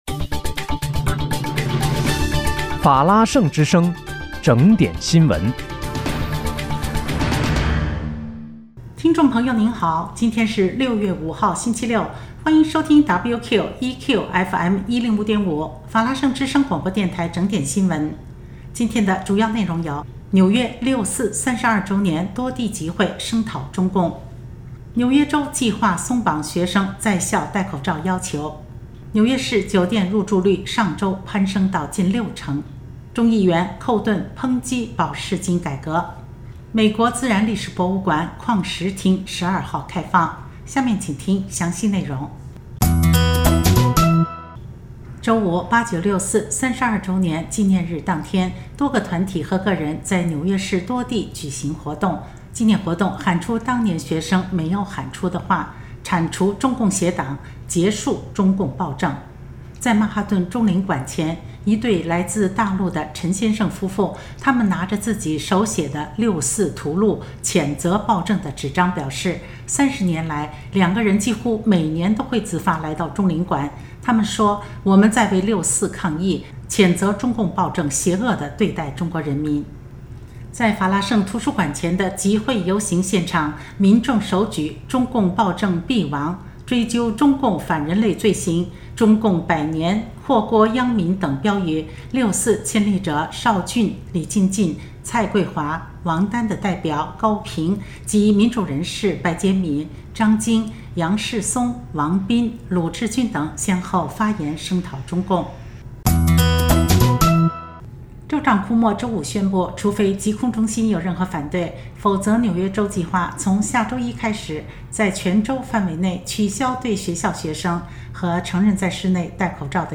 6月5日（星期六）纽约整点新闻